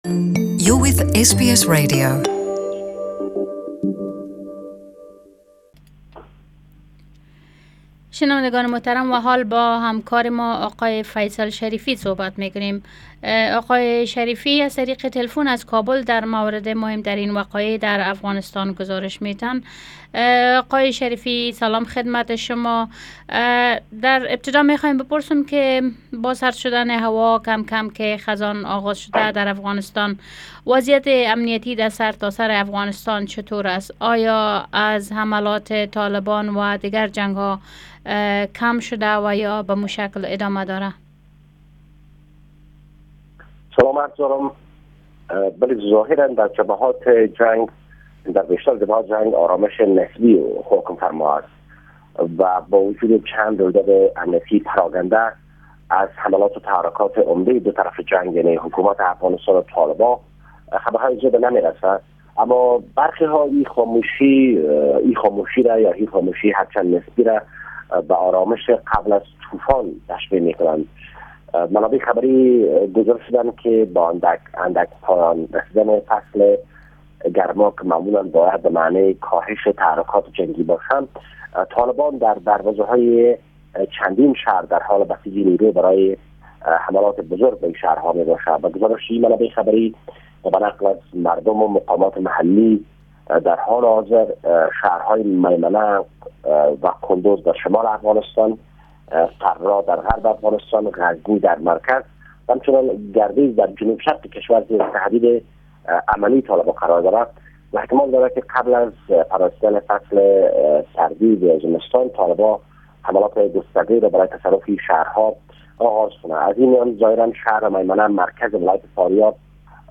REPORT FROM KABUL